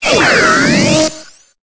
Cri de Motisma dans Pokémon Épée et Bouclier.